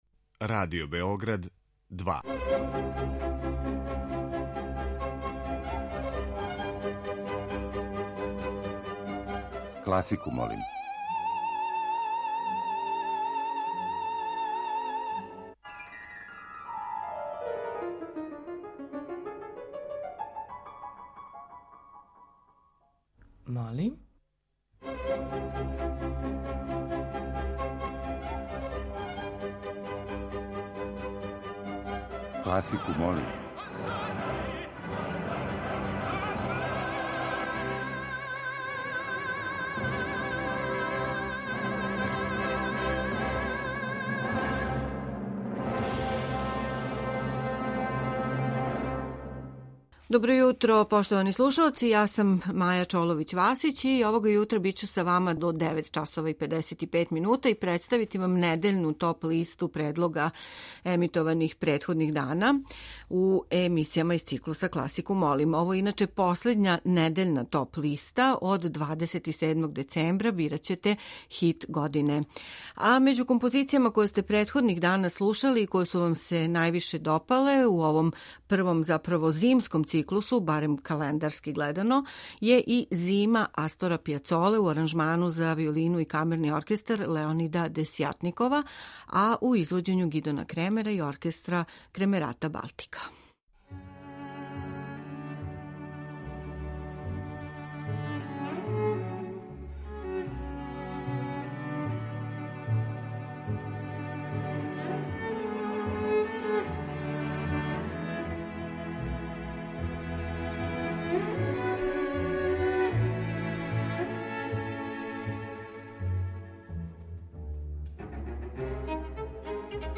Међу њима је и неколико духовитих и шаљивих примера композиторске и извођачке креације, као део овонедељне теме, а слушаћете и четири снимка са концерата одржаних у оквиру 55. фестивала „Мокрањчеви дани".